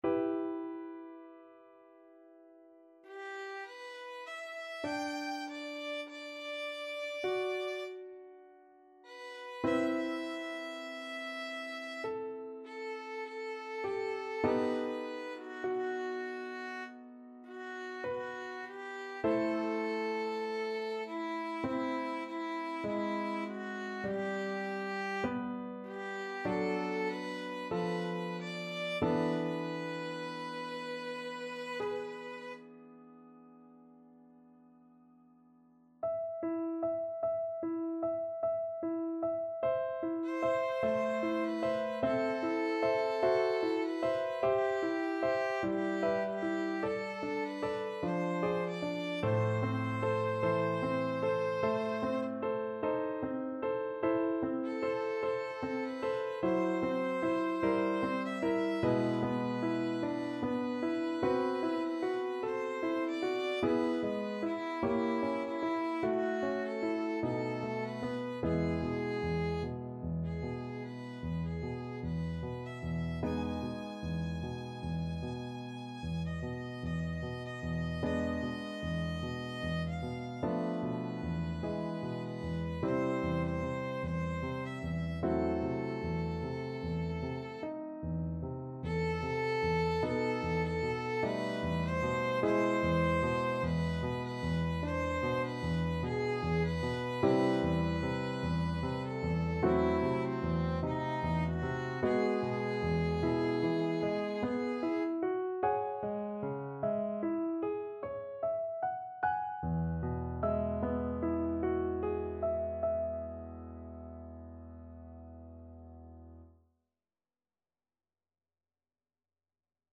Violin version
~ = 100 Lento =50
4/4 (View more 4/4 Music)
Classical (View more Classical Violin Music)